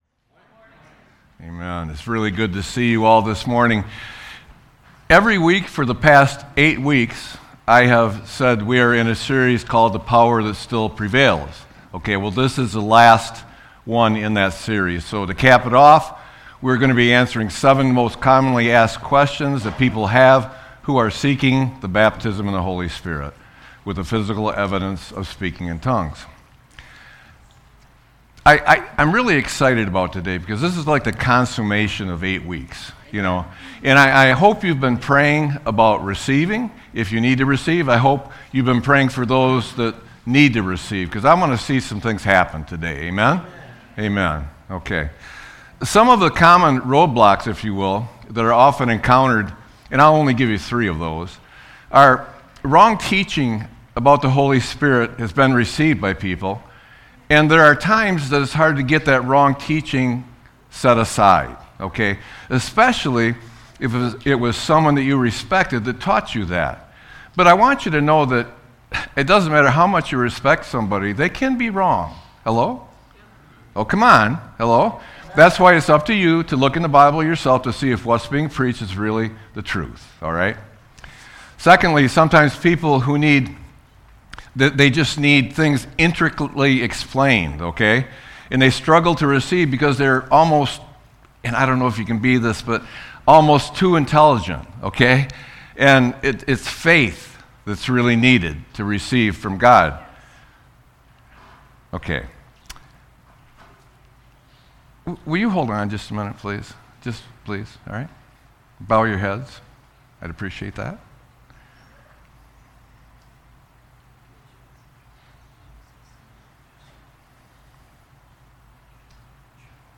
Sermon-4-19-26.mp3